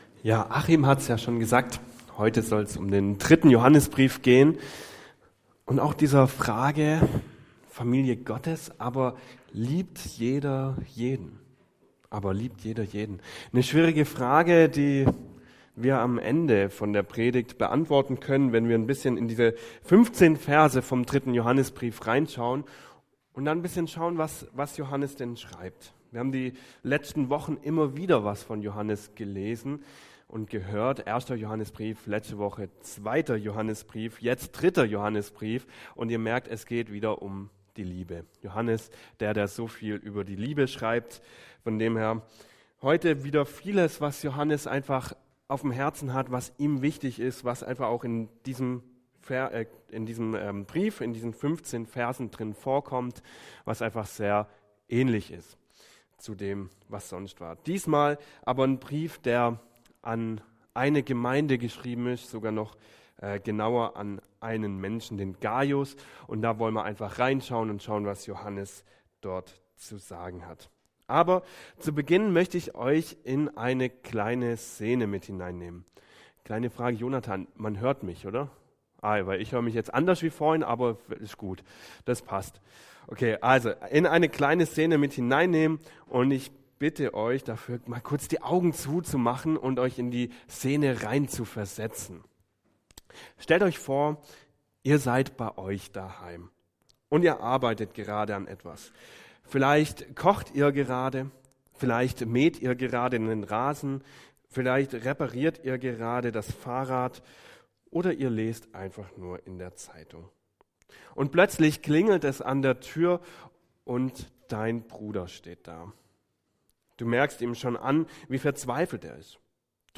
Predigt 26.10.2025 (Kopie) - SV Langenau